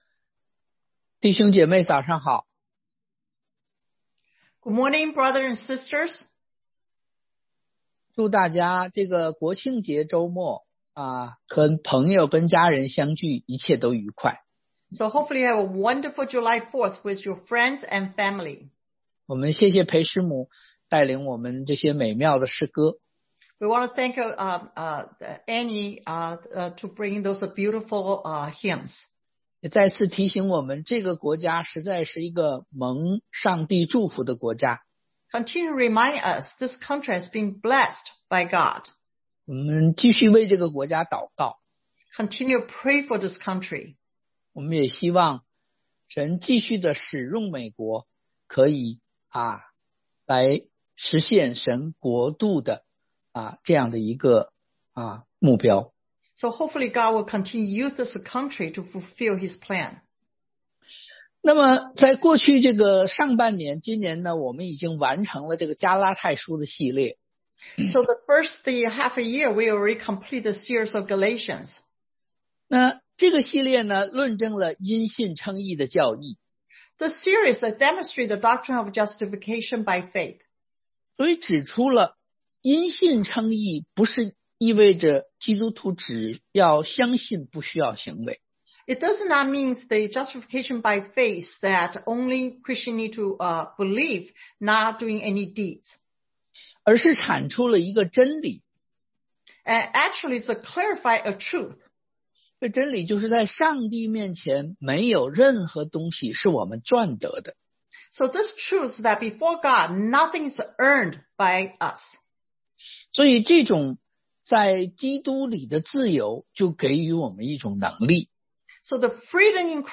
Mark 3:13-19 Service Type: Sunday AM What Is Discipleship Training?